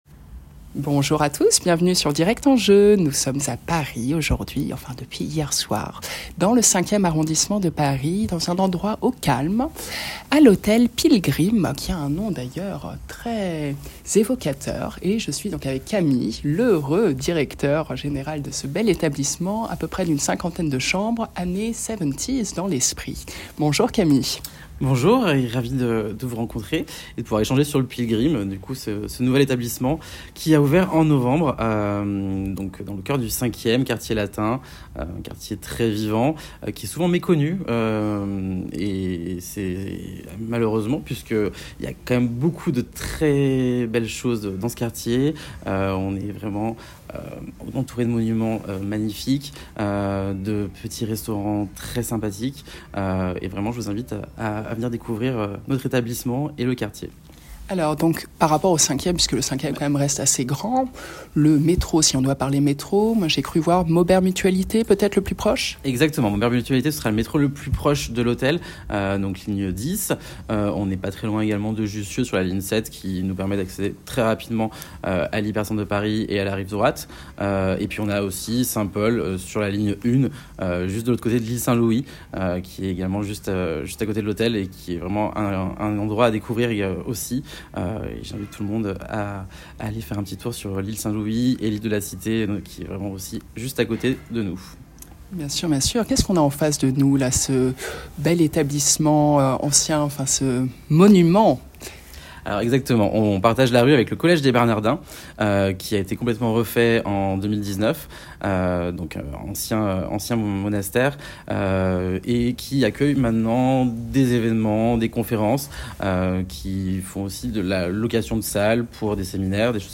Interview à l'hôtel Pilgrim Paris